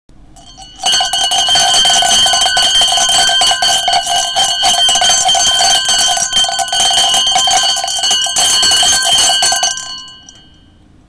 Halloween noisemakers